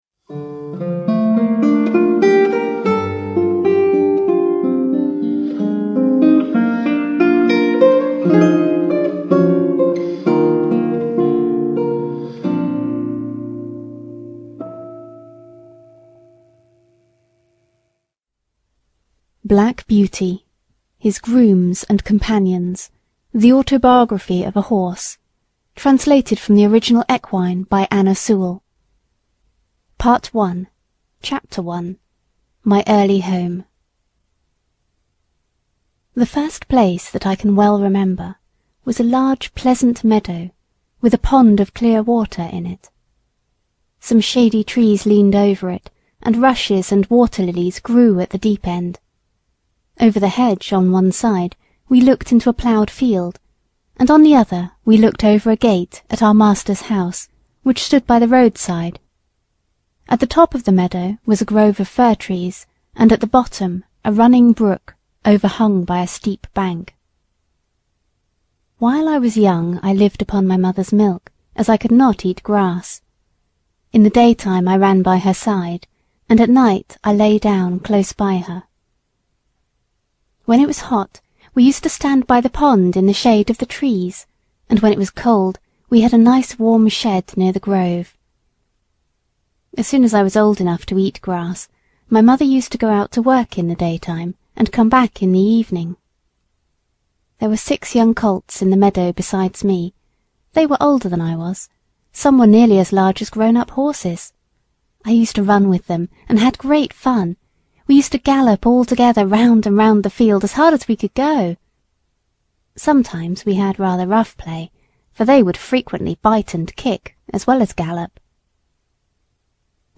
Black Beauty (ljudbok) av Anne Sewell